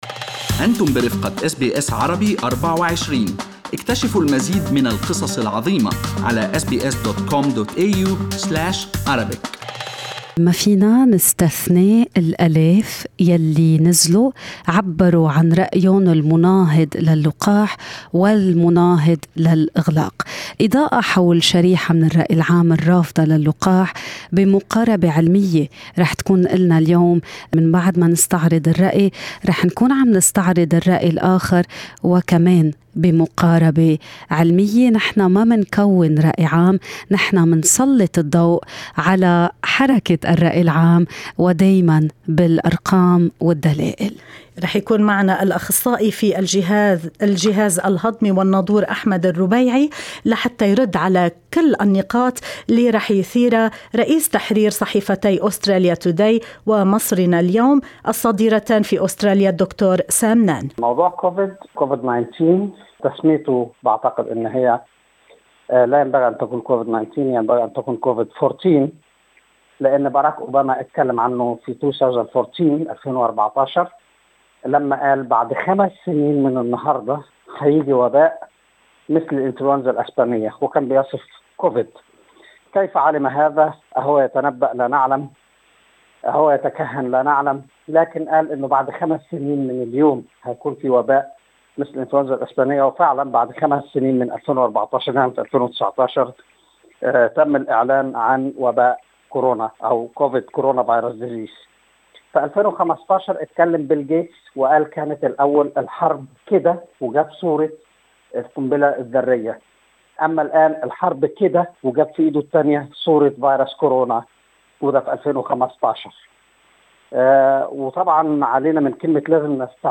مقاربة علمية للإجابة حول مخاوفكم وتساؤلاتكم والإضاءة حول كل ما تريدون معرفته في ما يخص فيروس كوفيد-19 بما في ذلك نظرية المؤامرة وفاعلية اللقاح بمواجهة الفيروس، في هذا الحوار الغني والمستند الى الأدلة العلمية.